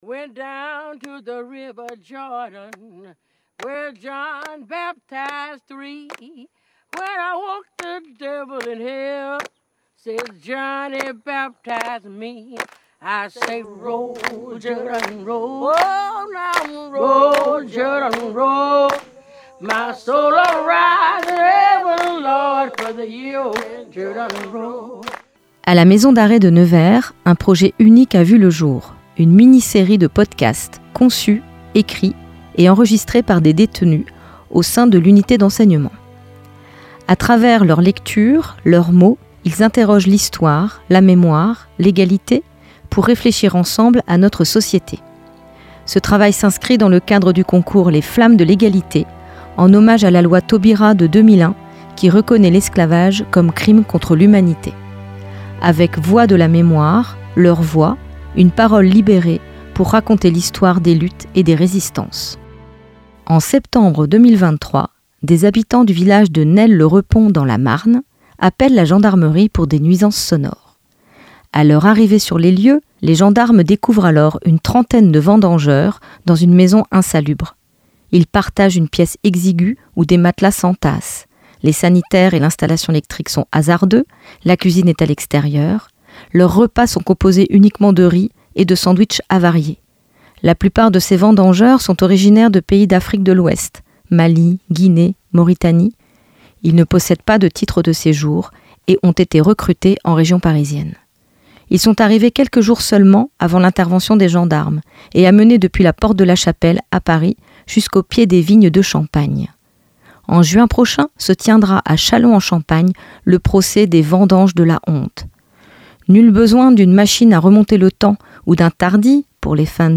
Ces productions radiophoniques, enregistrées en détention, donnent à entendre des recherches, des lectures et des réflexions portées par les participants eux-mêmes, qui se sont emparés de ce sujet universel et toujours actuel.